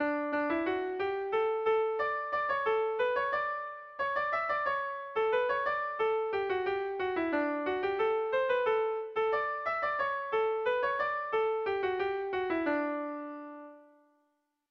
Bertso melodies - View details   To know more about this section
Erlijiozkoa
ABDE